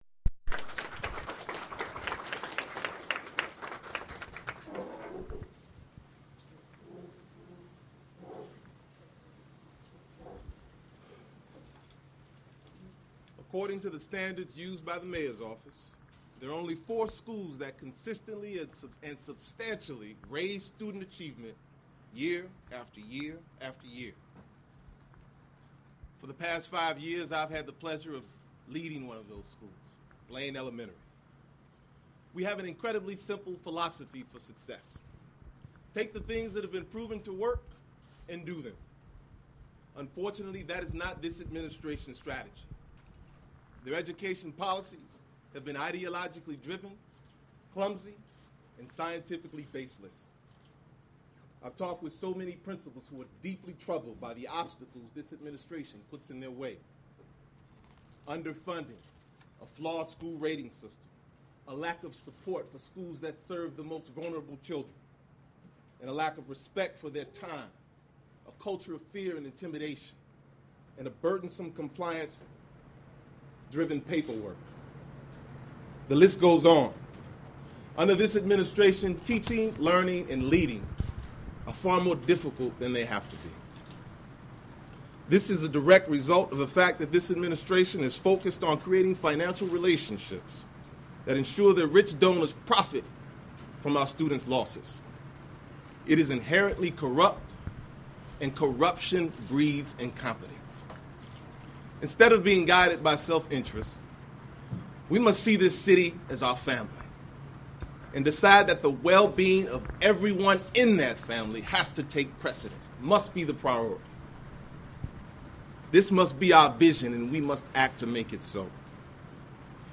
The press availability, which was heavily attended by the media, was at the North Side Wishbone Restaurant.